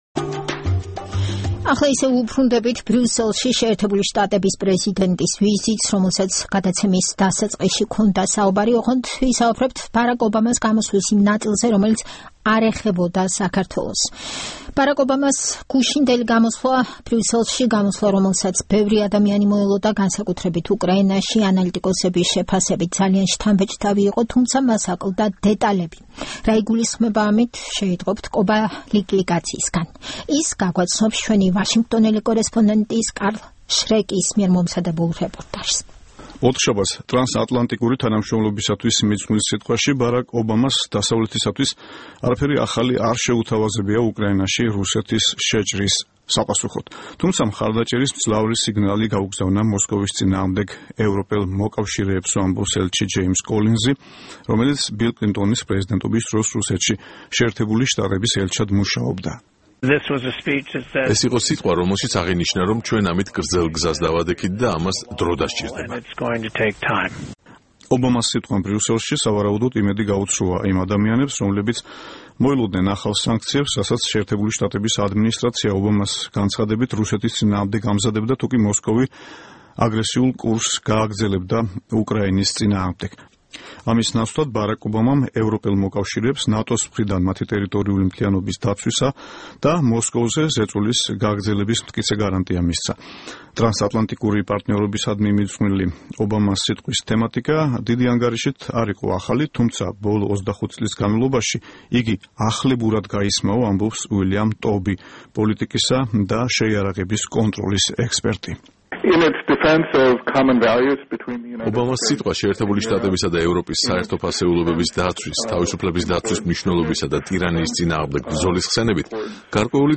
ბარაკ ობამას ბრიუსელის სიტყვა